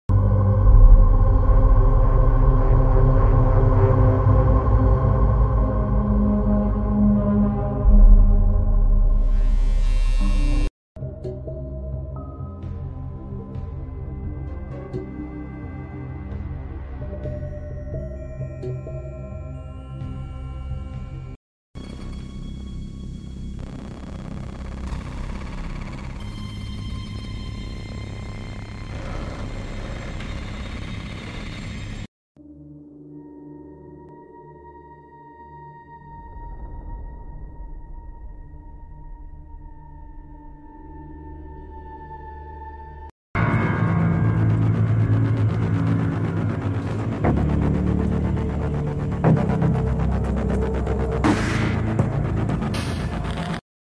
main menu screen music